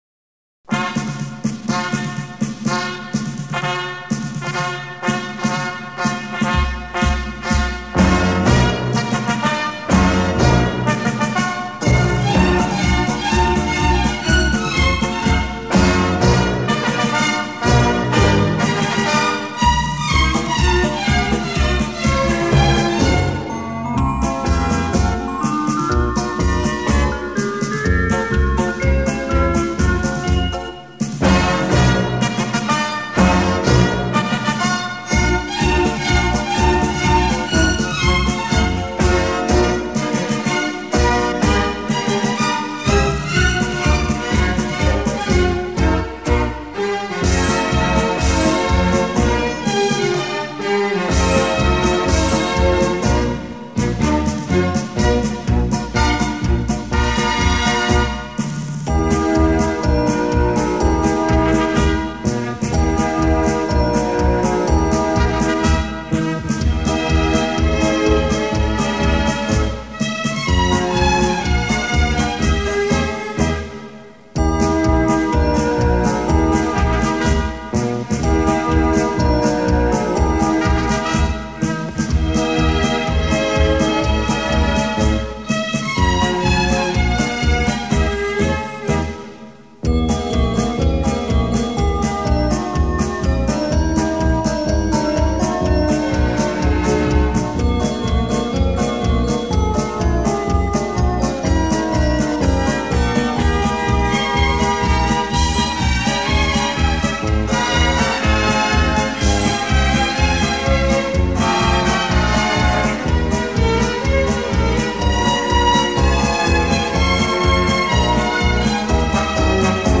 【专辑类别】电子音乐